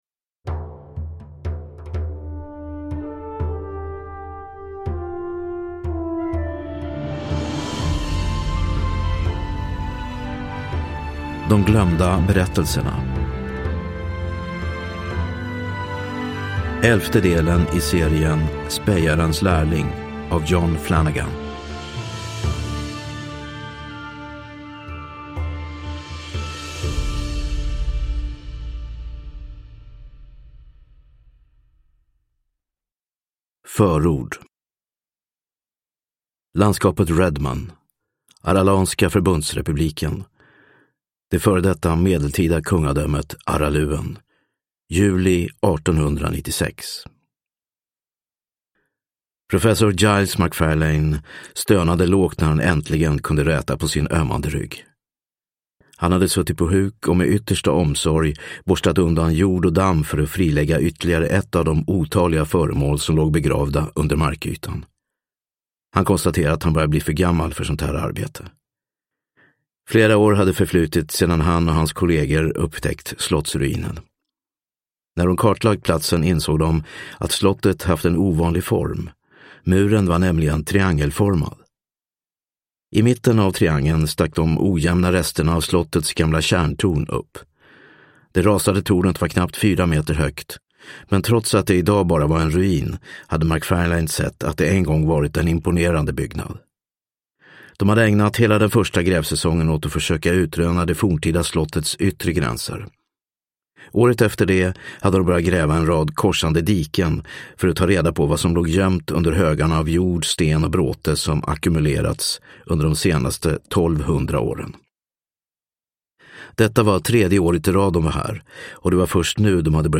De glömda berättelserna – Ljudbok – Laddas ner